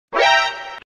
На этой странице представлены звуки восклицательного знака в разных стилях и вариациях: от стандартных системных уведомлений до необычных интерпретаций.
Звук восклицательного знака для пупа rytp